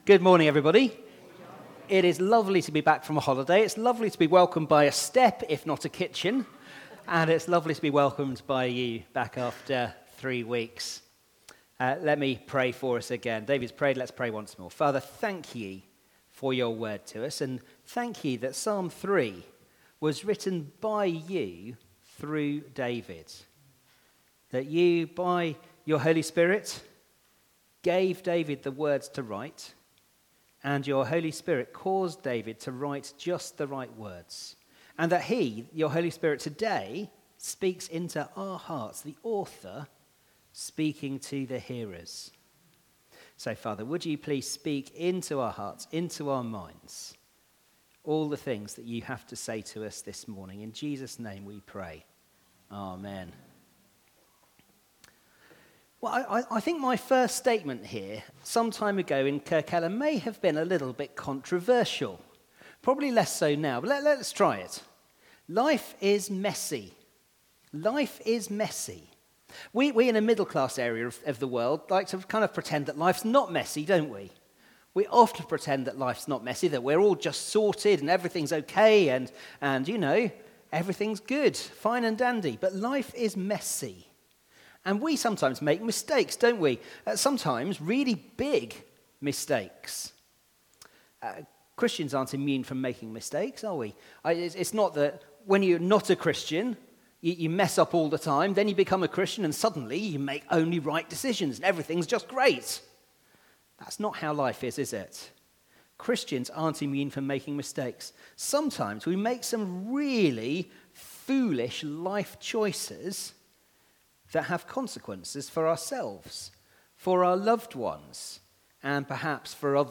Media Library We record sermons from our Morning Prayer, Holy Communion and Evening services, which are available to stream or download below.
Media for Morning Service on Sun 20th Aug 2023 10:45 Speaker